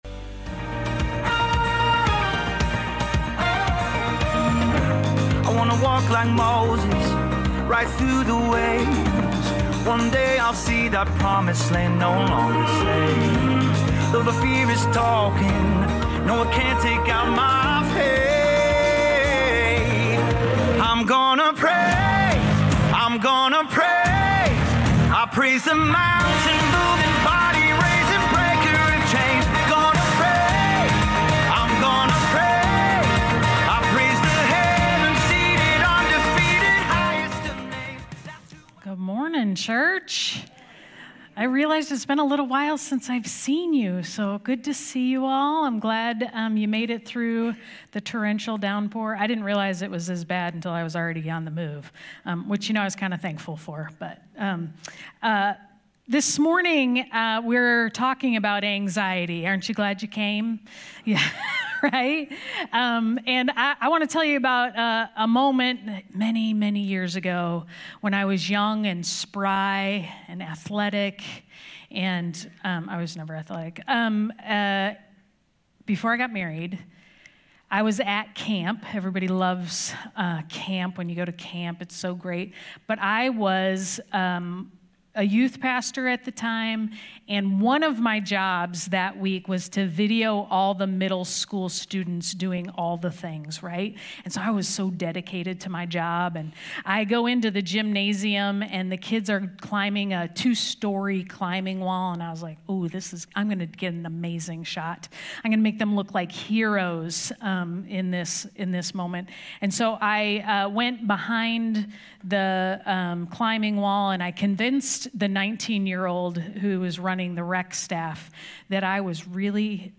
Sermons | Advent Presbyterian Church